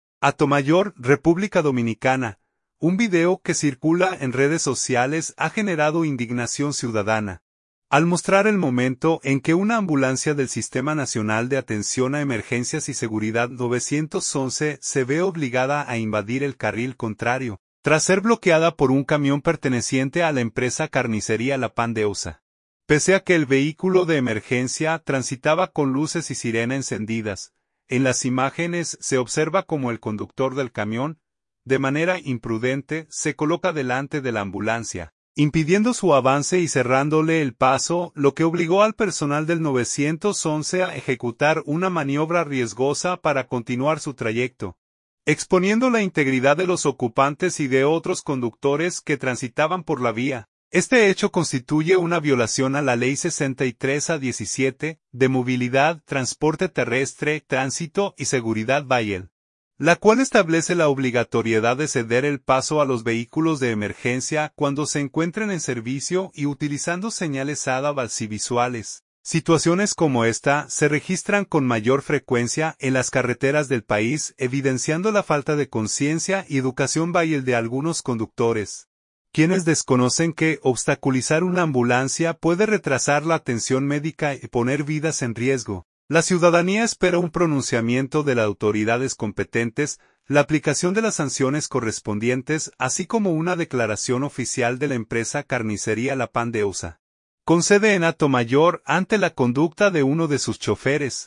HATO MAYOR, República Dominicana. — Un video que circula en redes sociales ha generado indignación ciudadana, al mostrar el momento en que una ambulancia del Sistema Nacional de Atención a Emergencias y Seguridad 911 se ve obligada a invadir el carril contrario, tras ser bloqueada por un camión perteneciente a la empresa Carnicería La Ponderosa, pese a que el vehículo de emergencia transitaba con luces y sirena encendidas.